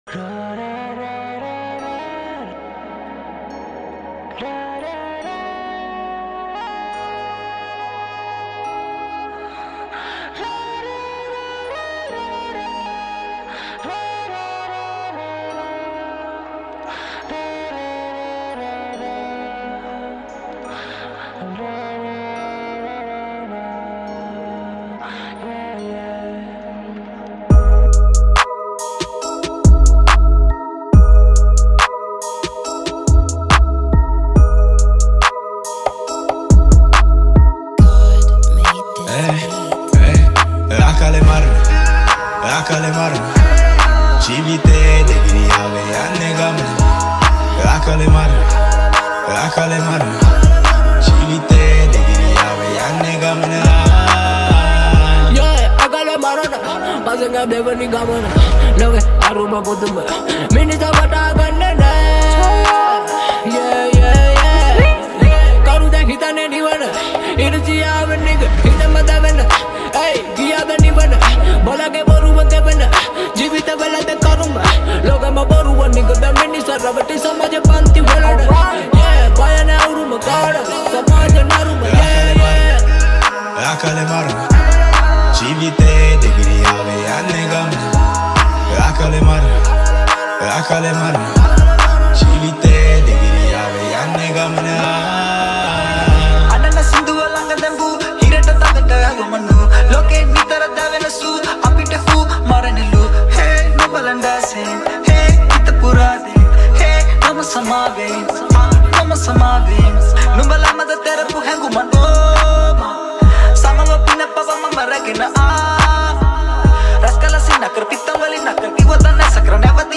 Rap 2020 download mp3.